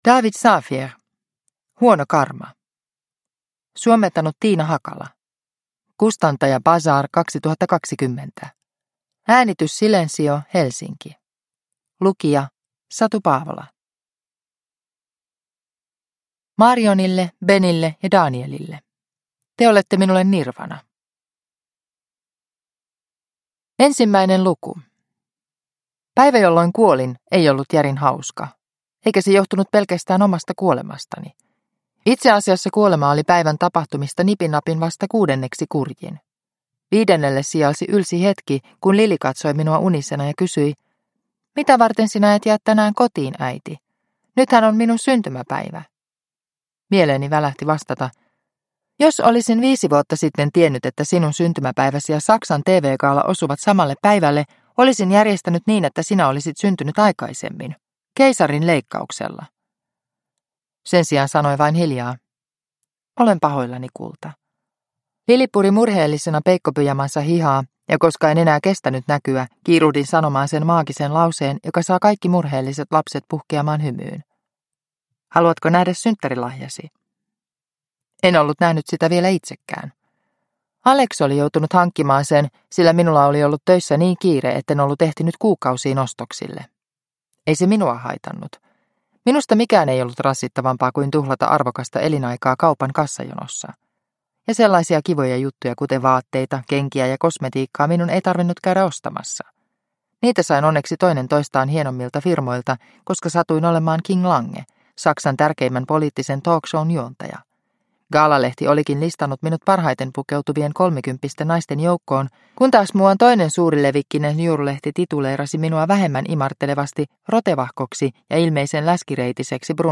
Huono karma – Ljudbok